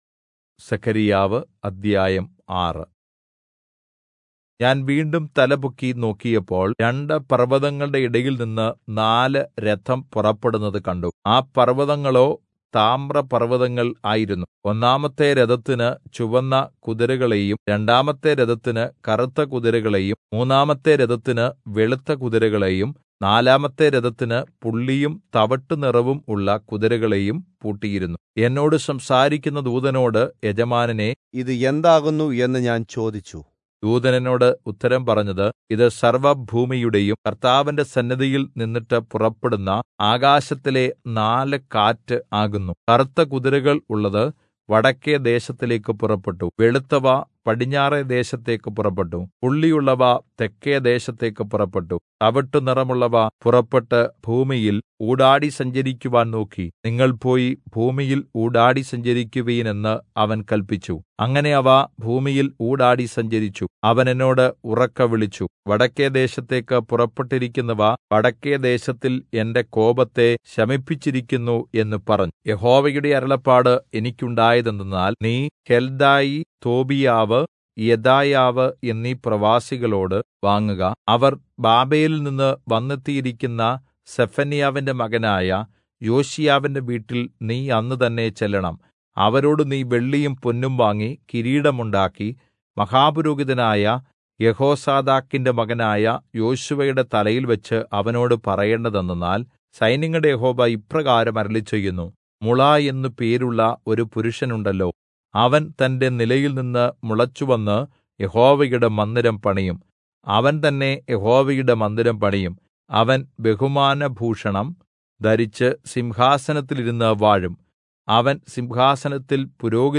Malayalam Audio Bible - Zechariah 12 in Irvml bible version